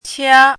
chinese-voice - 汉字语音库
qia1.mp3